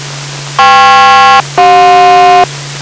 Hat jemand den Alarmruf von Serie Notruf Califonia
und lt. der Seite der Originalton: